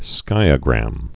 (skīə-grăm)